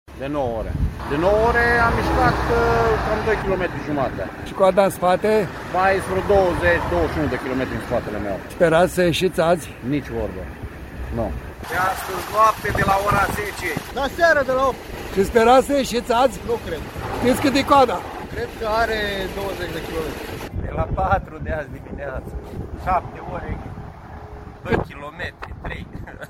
Nu este singurul care se plânge de coloana imensă de camioane:
16mar-13-CORESP-AR-voxuri-de-pe-autostrada.mp3